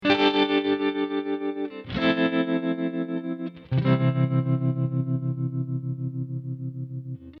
Cool Vibrating Guitar Sound That Makes A Great Ringtone.